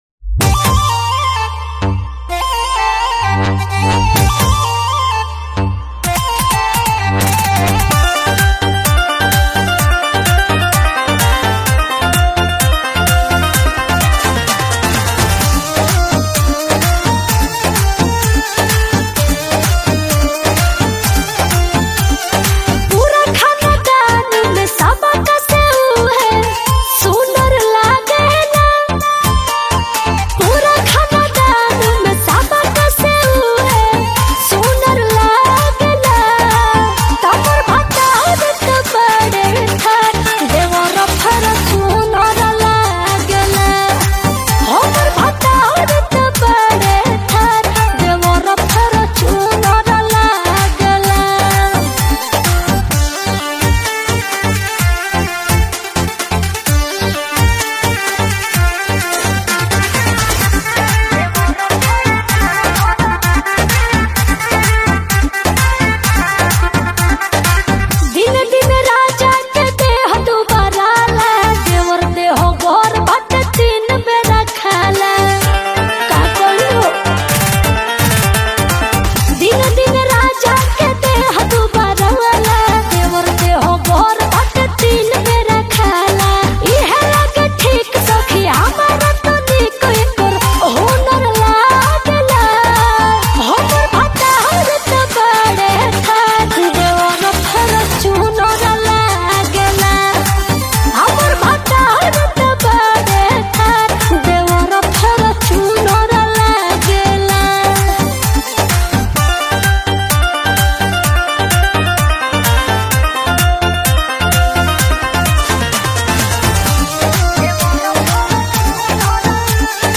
Bhojpuri